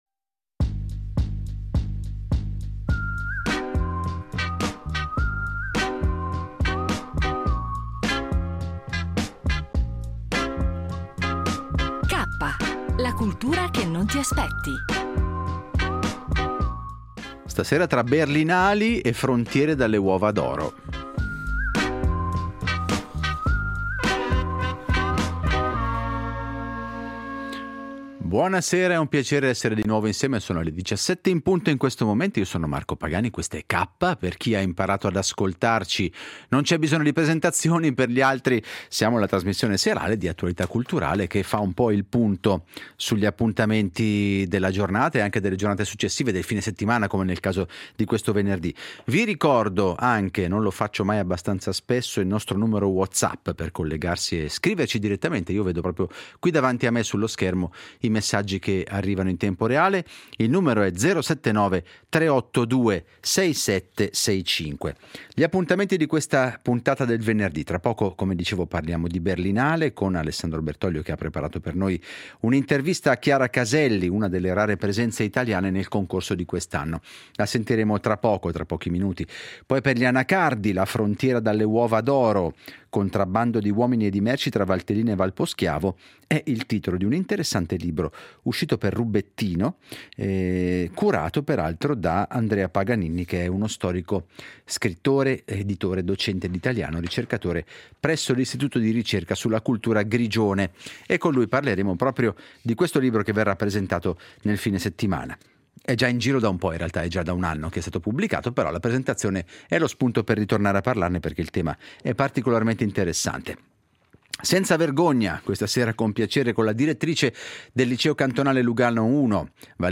Partiamo oggi dalla 76esima Berlinale , con un’intervista a Chiara Caselli sul cinema italiano e il suo ruolo nel film “Nina Rosa”.